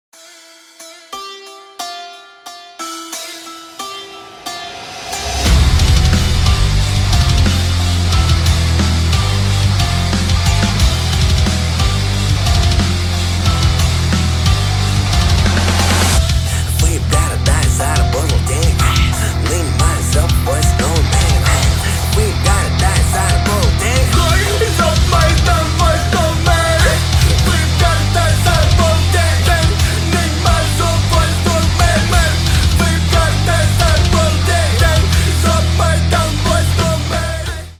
Рок Металл # Ремикс